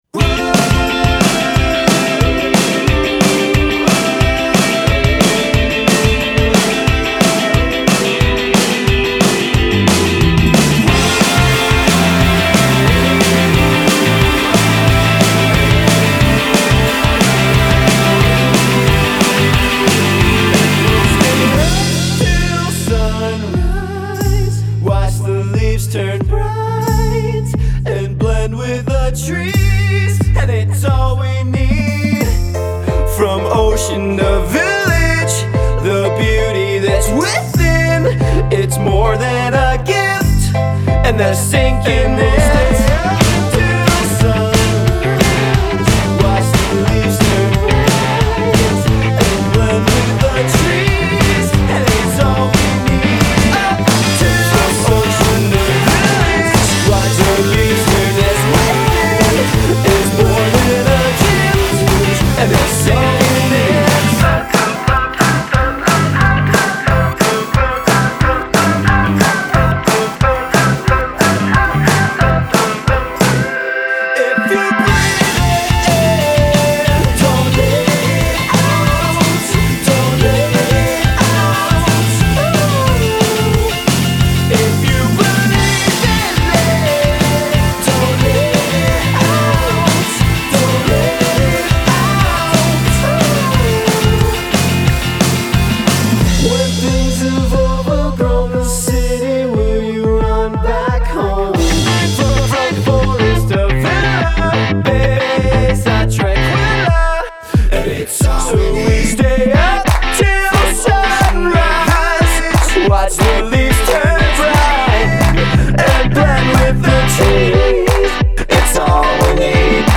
energetic